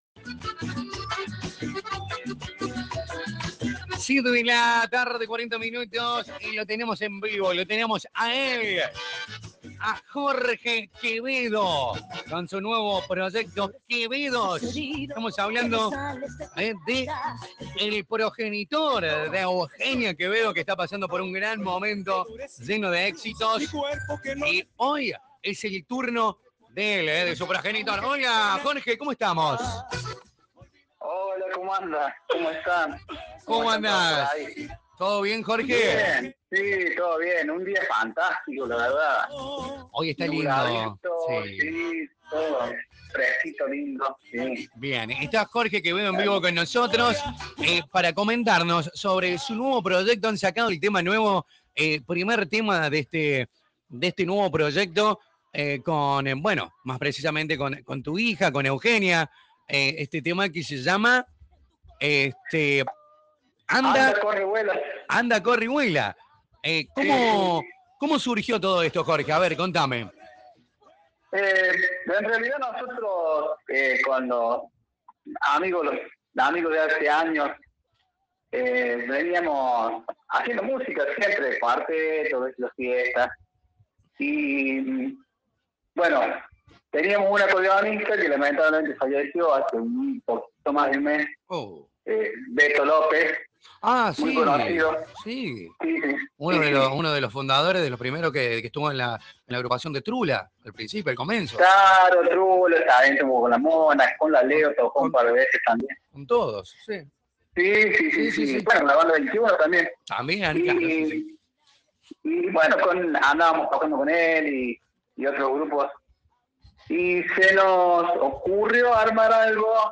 nota telefónica
En la entrevista